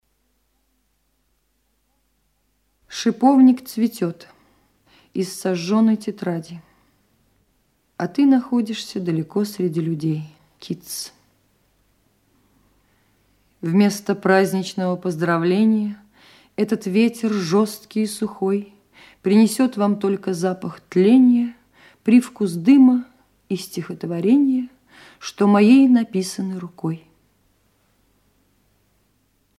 6. «(МБ) Исп. Маргарита Терехова – А.Ахматова. Шиповник цветет» /